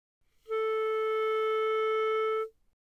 Eb Clarinet
EbClarA4.wav